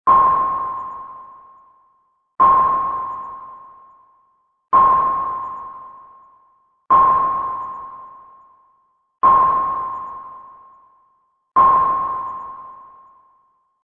echolot2.mp3